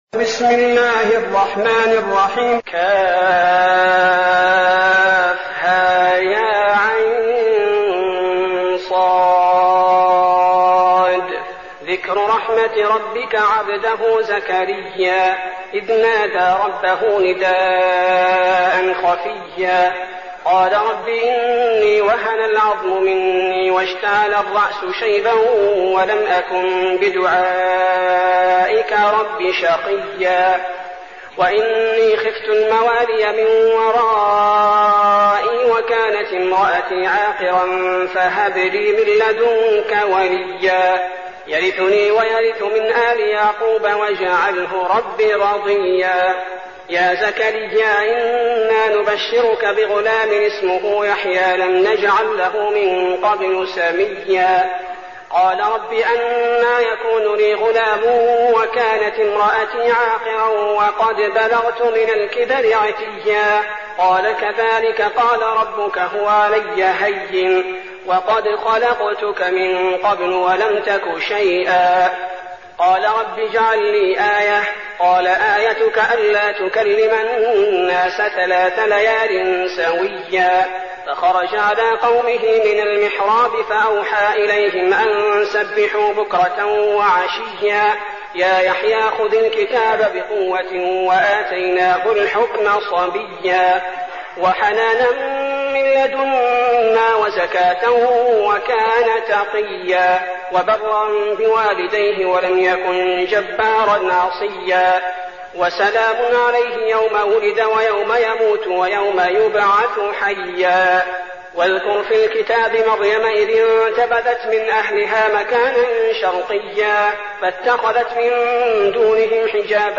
المكان: المسجد النبوي الشيخ: فضيلة الشيخ عبدالباري الثبيتي فضيلة الشيخ عبدالباري الثبيتي مريم